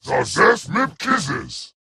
Jiralhanae voice clip from Halo: Reach.